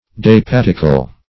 Search Result for " dapatical" : The Collaborative International Dictionary of English v.0.48: Dapatical \Da*pat"ic*al\, a. [L. dapaticus, fr. daps feast.] Sumptuous in cheer.